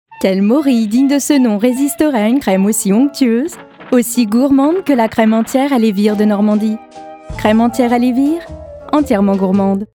Démo voix pétillante